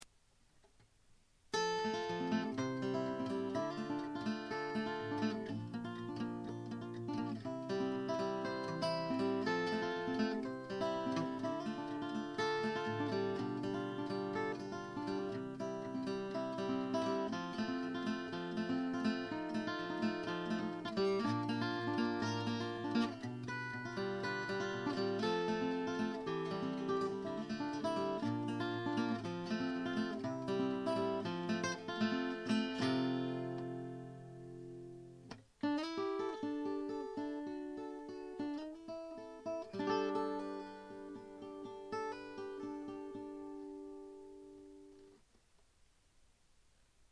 ICレコーダー任せのお手軽録音なので
■サムピック使用でスリーフィンガー。"
スリーフィンガー　VG
どれも一発録り、、、。
サムピック使用では、思いのほかVGが良い味を出してくれる感じ。
スキッと輪郭のはっきりした印象。
ICレコーダーにステレオマイクをつないで